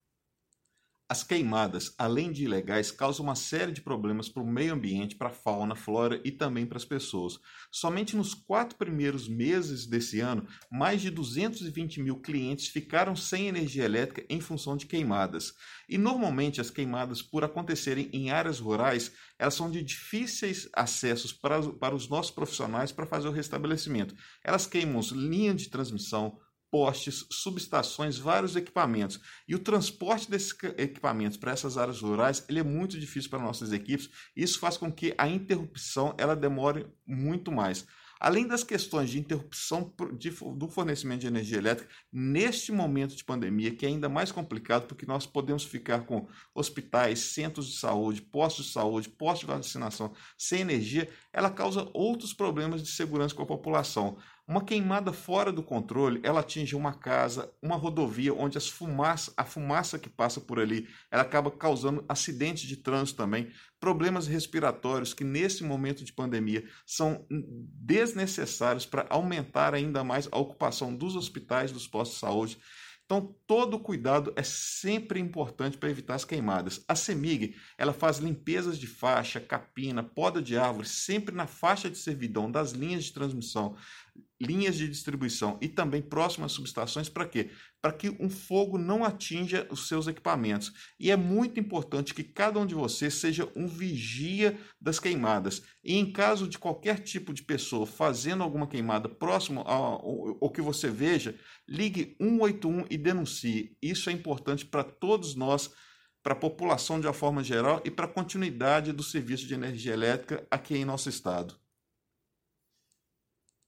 Ouça a sonora